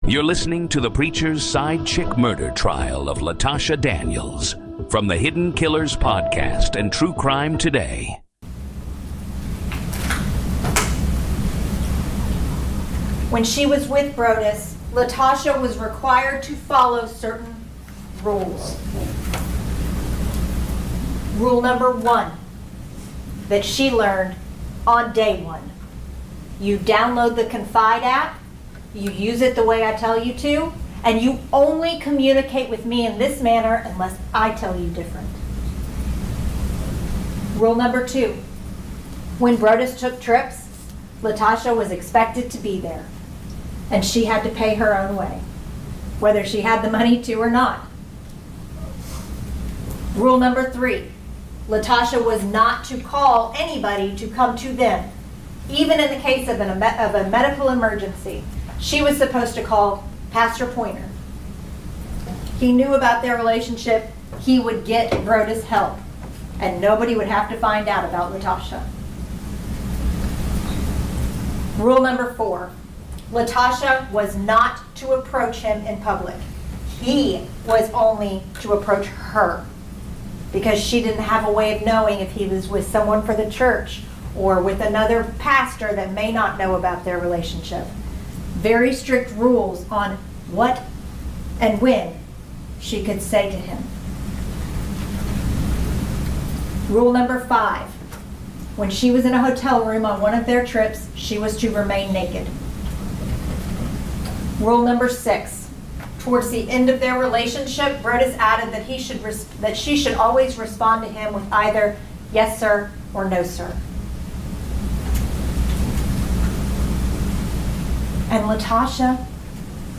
Raw, unedited courtroom coverage
Each recording features the full day’s testimony, witness questioning